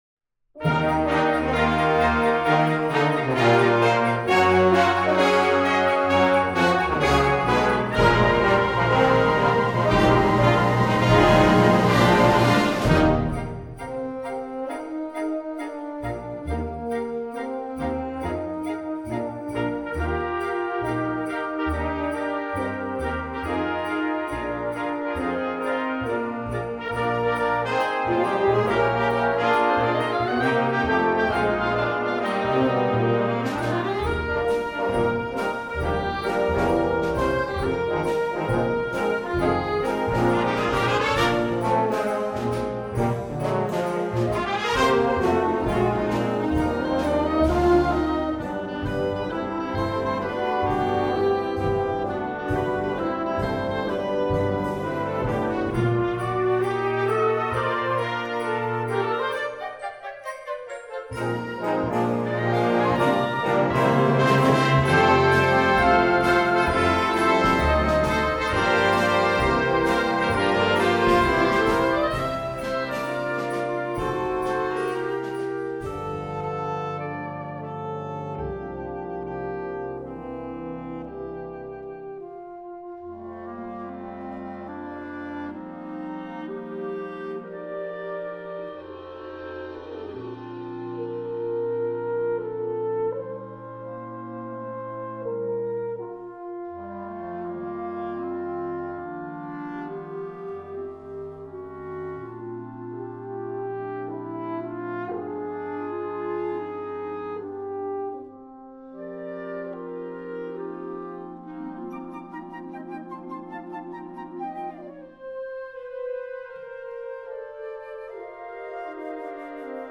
Gattung: Weihnachtslieder
Besetzung: Blasorchester